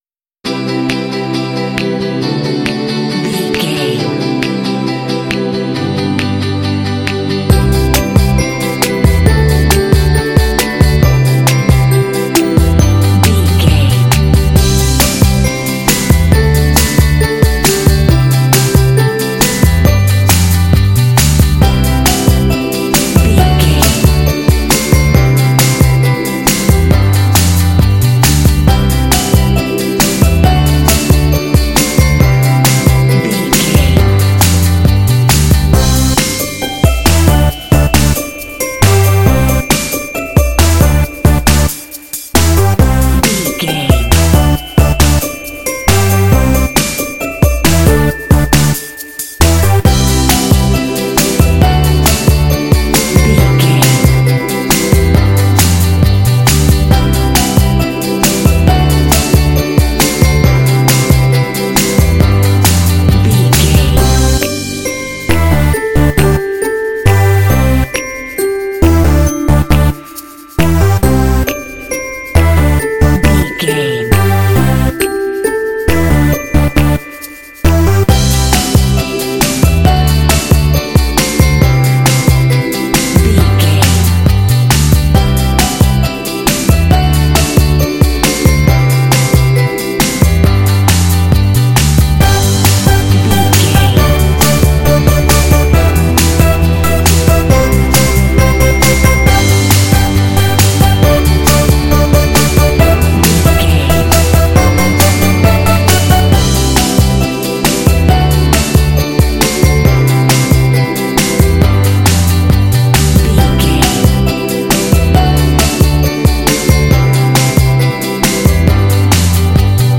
Uplifting
Ionian/Major
energetic
playful
cheerful/happy
contemporary underscore